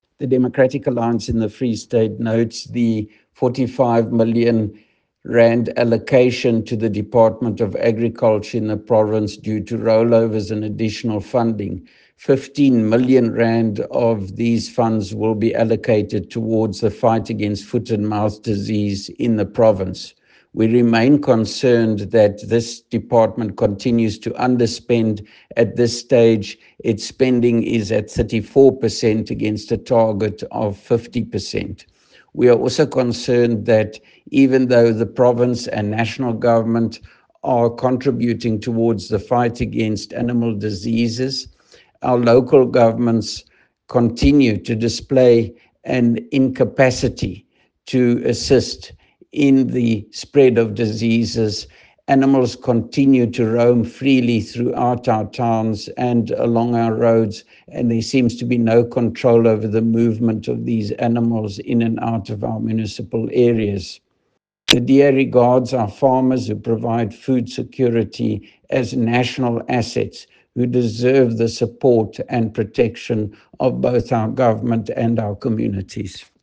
Afrikaans soundbites by Roy Jankielsohn MPL and Sesotho soundbite by Jafta Mokoena MPL